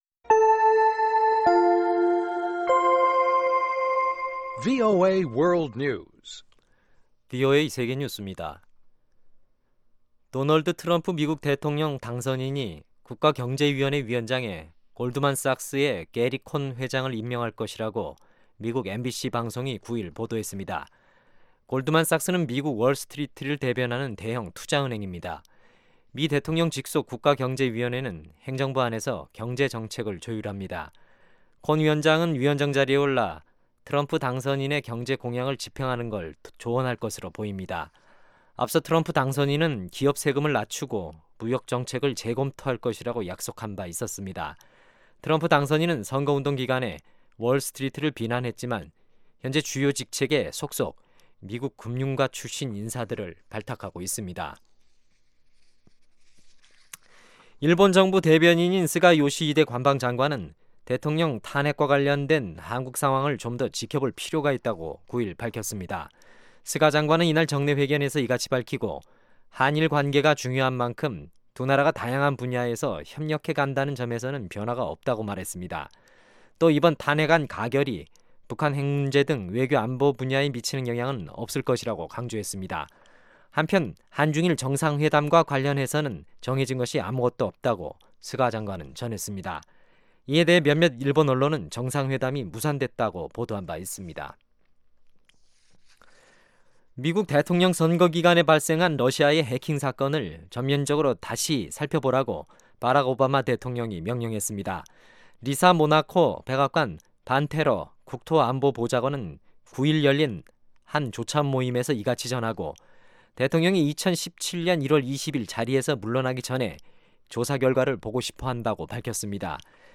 VOA 한국어 방송의 아침 뉴스 프로그램 입니다. 한반도 시간 매일 오전 5:00 부터 6:00 까지, 평양시 오전 4:30 부터 5:30 까지 방송됩니다.